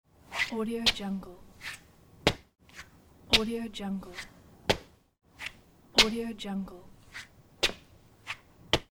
دانلود افکت صوتی ساییدن کفش کتانی روی سرامیک
Foot Scuffing Sneakers On Tiles royalty free audio track is a great option for any project that requires human sounds and other aspects such as a foot, scuffing and sneakers.
Sample rate 16-Bit Stereo, 44.1 kHz